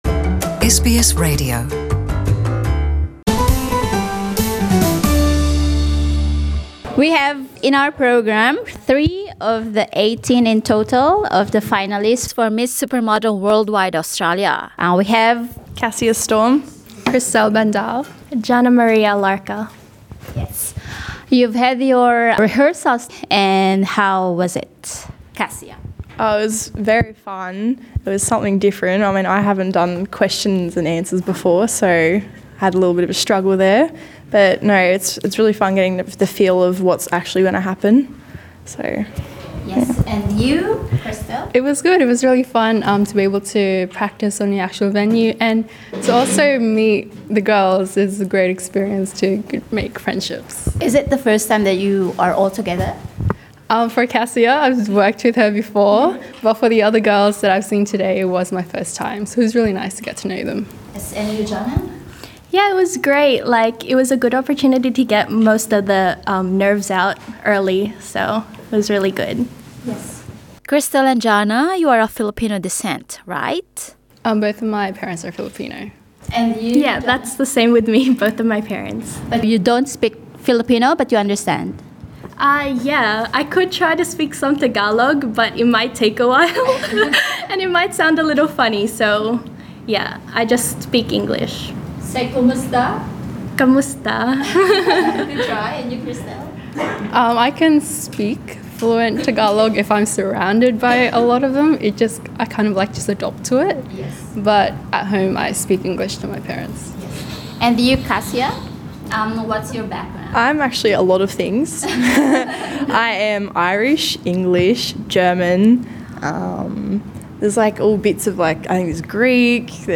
SBS Filipino speaks with three of the 18 finalists for the said modelling competition.